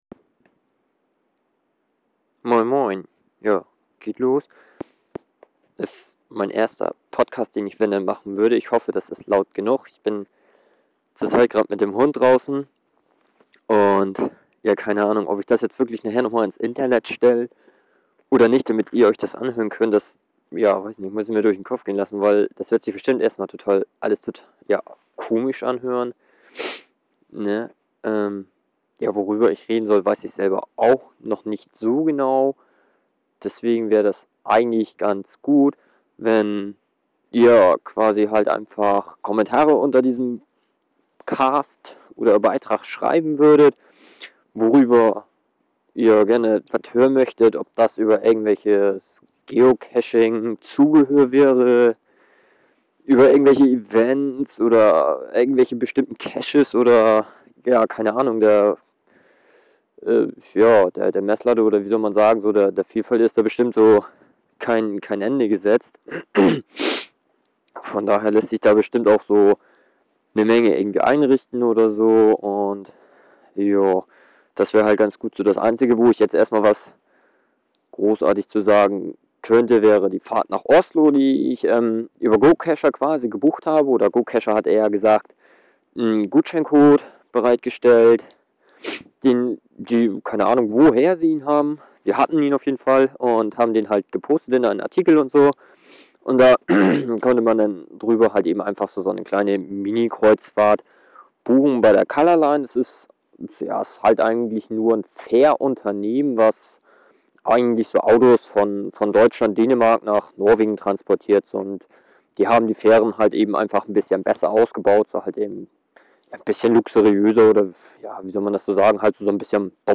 Hmm also schnappe ich mir den Hund und wir gingen nochmal eine kleine Runde hier durchs Dorf.
Dabei ist nun mein erster Cast entstanden.